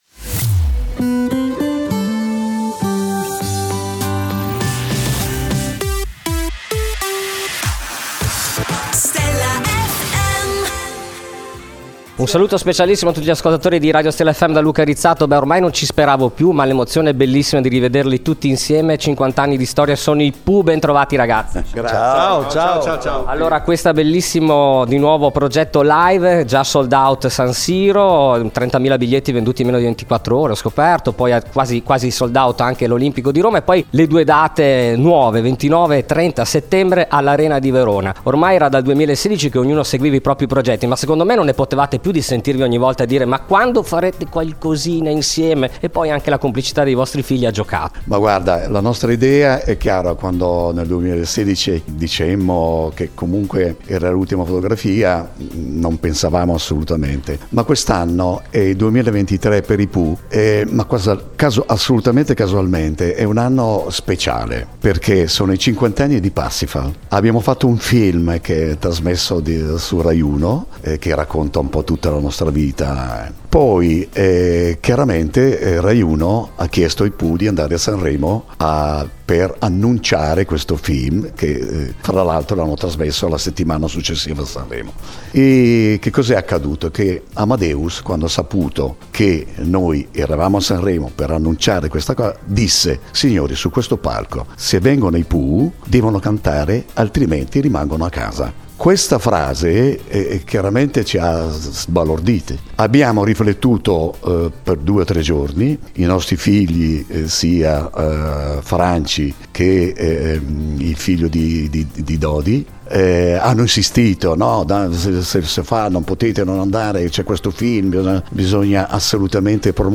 Intervista I Pooh | Stella FM
Intervista esclusiva dell’inviato per Stella FM ai Pooh. .